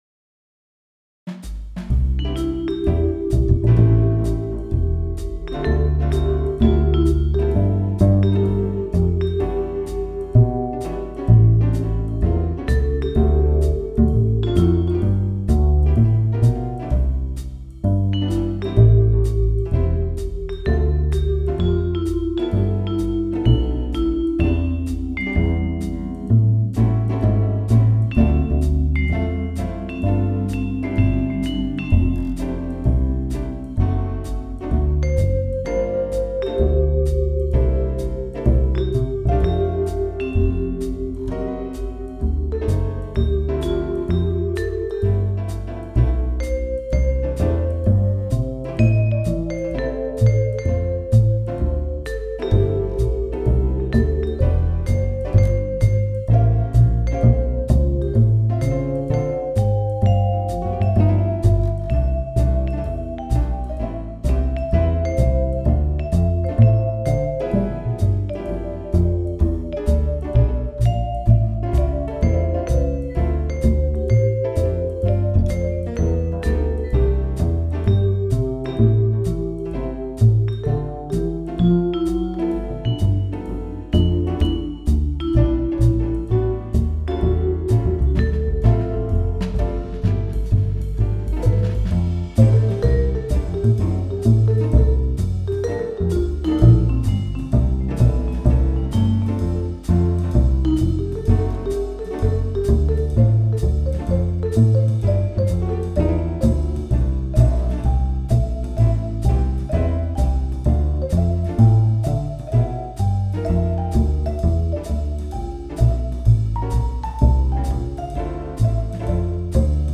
Jahresanfang mit ausgewählten italienischen Musikstücken, ein Stück pro Jahresmonat, um den richtigen Ton für den Jahresanfang anzustimmen.